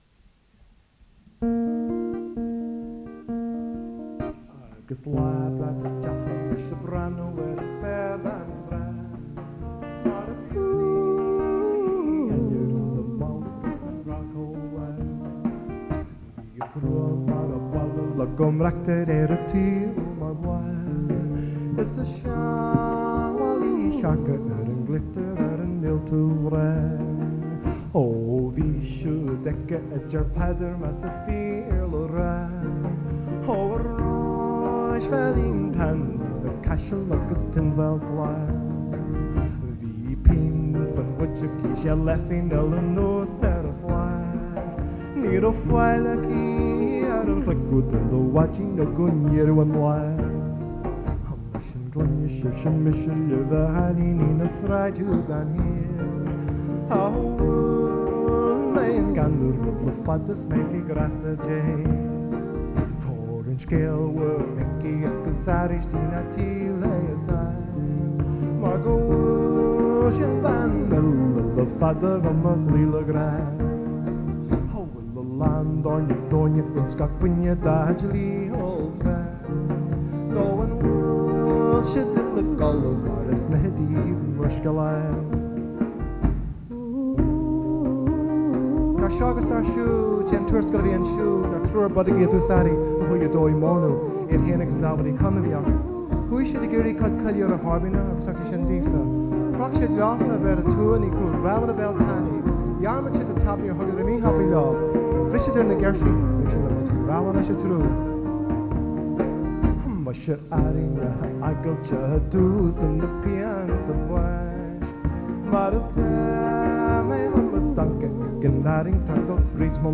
It also seems like an early form of the modern-day rap!
lead vocals and spoken part
percussion
vocal harmony and guitar
keyboards.